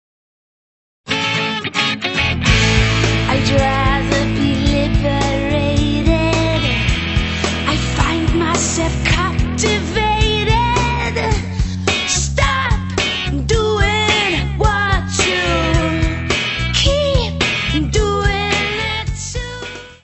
Área:  Pop / Rock